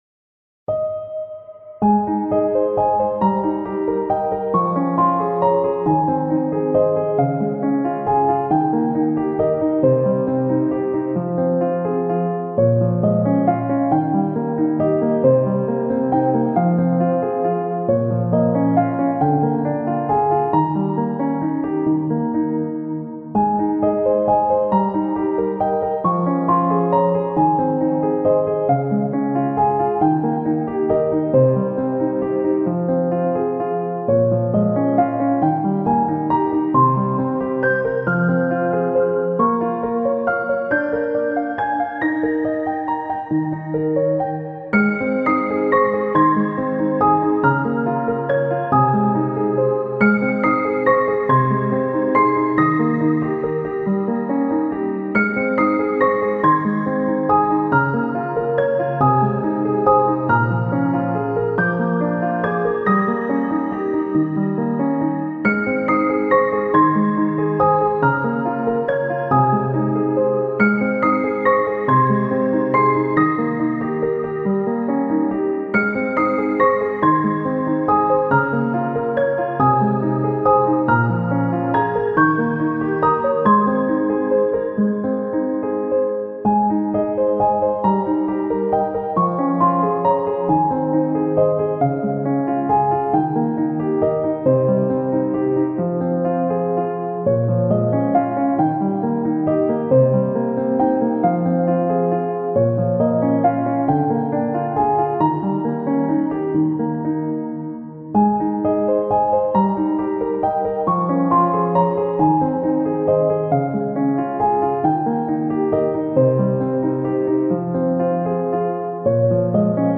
BGM
スローテンポロング明るい穏やか